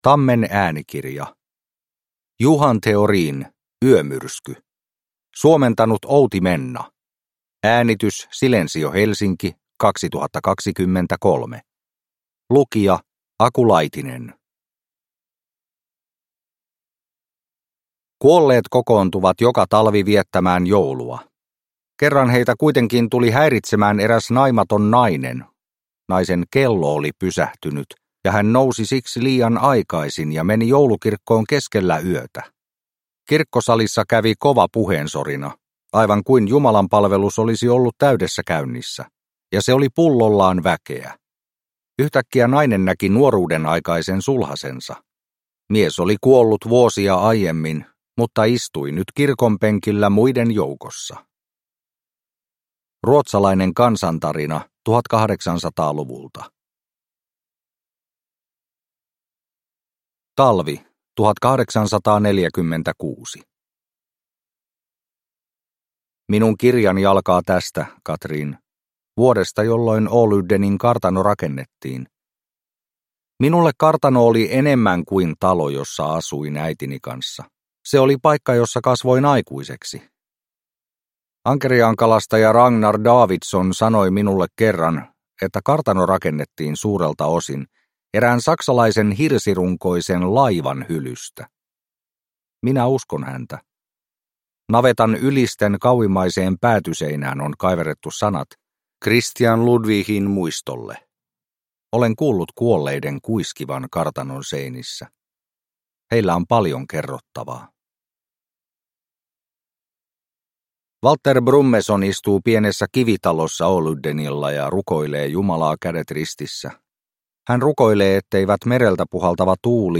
Yömyrsky – Ljudbok – Laddas ner